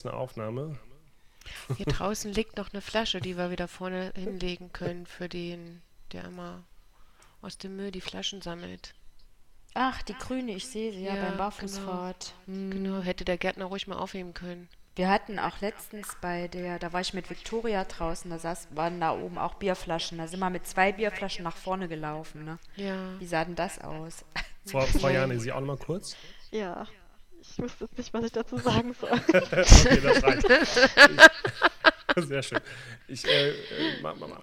Hall-Probleme bei Integration von drei externen Gästen über StudioLink in Rodecaster Pro II und Ultraschall
Allerdings habe ich bei allen Interviewpartnern einen gut hörbaren Hall.
• Dadurch konnten aber auch die Gäste sich selbst leicht zeitverzögert hören – es entstand ein Hall bzw. Echo.